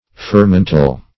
Fermental \Fer*ment"al\, a.